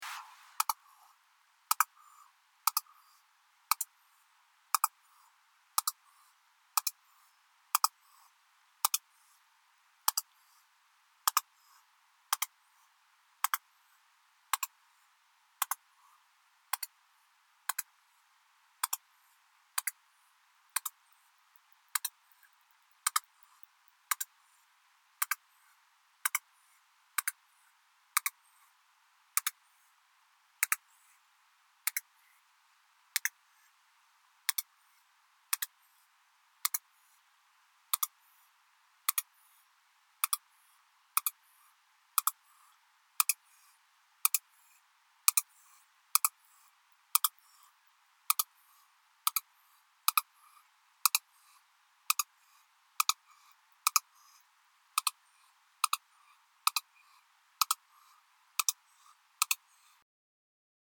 Les clics d’un cachalot.